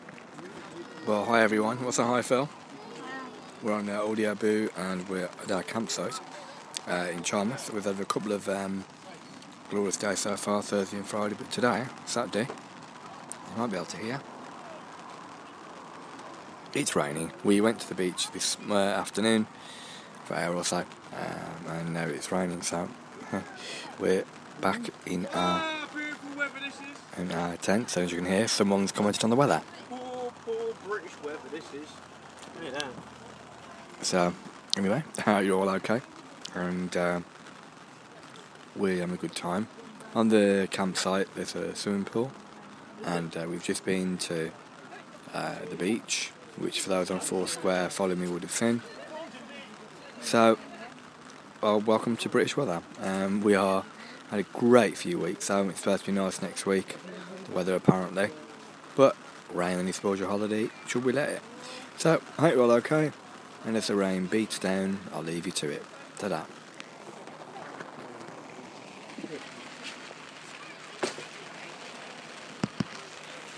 rain